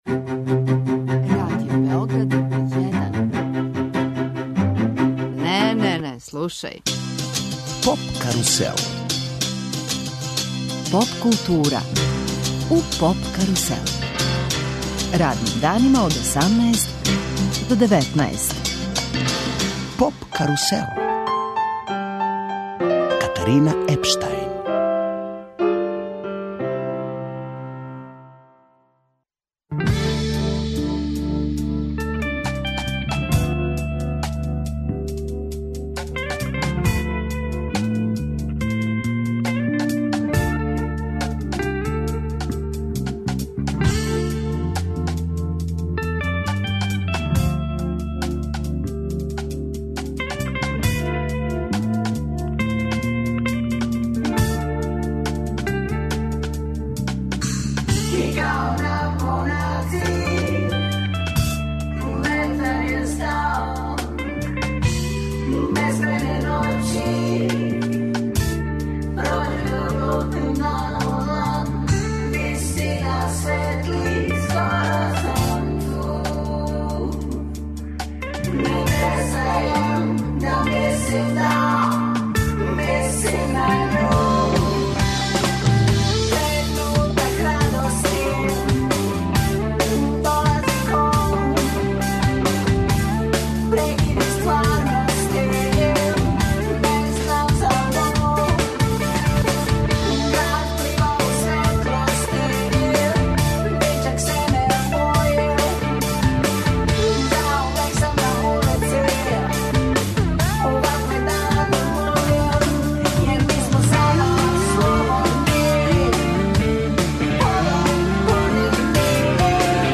У студију ће живо свирати млади бенд Каталеја.